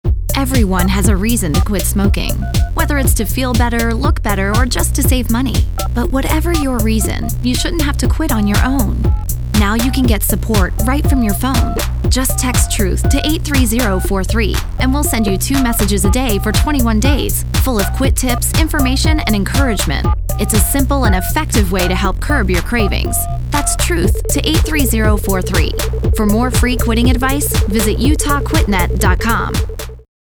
Also, attached are radio station produced advertisements for our program: